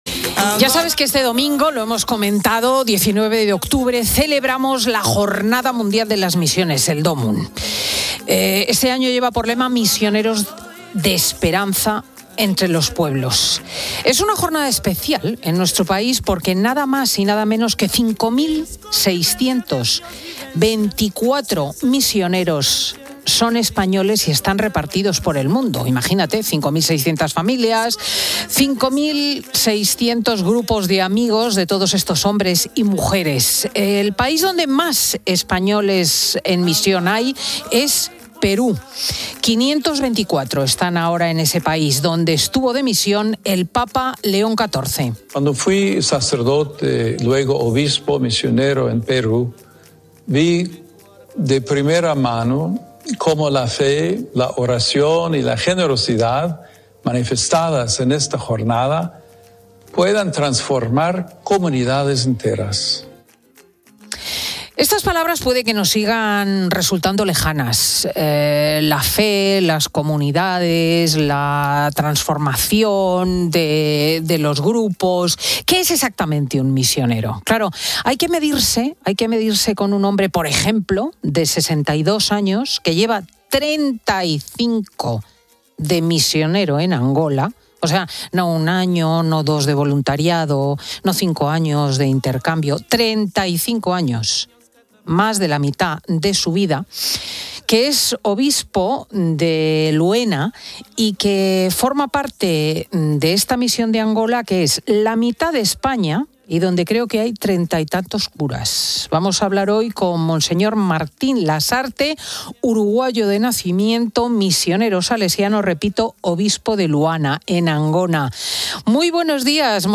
Entrevista Obispo Angola